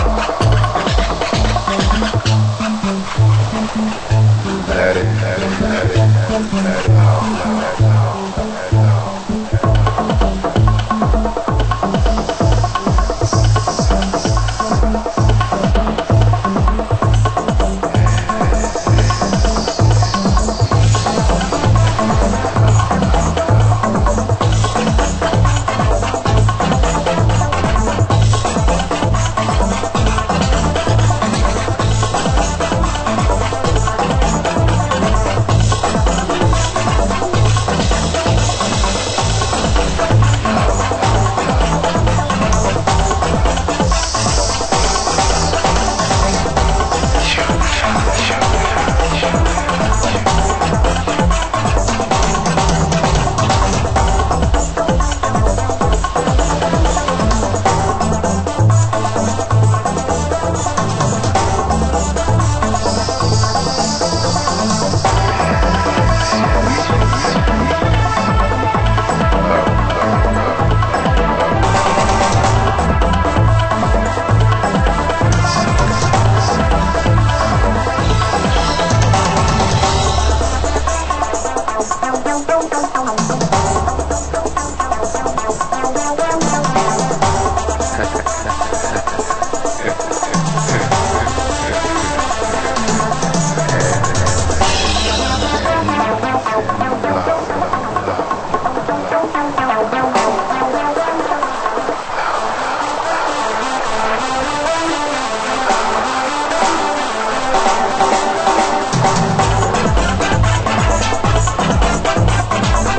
盤質：傷によるチリパチノイズ有/クリックノイズ出る箇所有